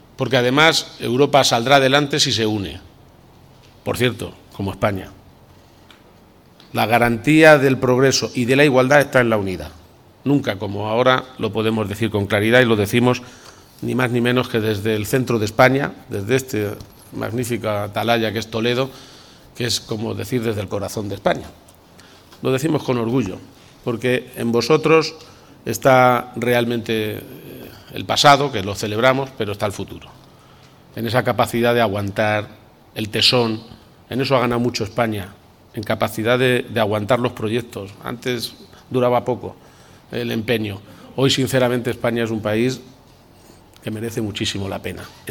Presidente Viernes, 21 Marzo 2025 - 8:15pm El presidente de Castilla-La Mancha, Emiliano García-Page, ha señalado esta tarde, en el acto de celebración del 75 aniversario de Laboratorios Indas, a la “unidad” como “la garantía del progreso”, tanto para Europa como para España. garcia-page_-_unidad_espana_y_europa.mp3 Descargar: Descargar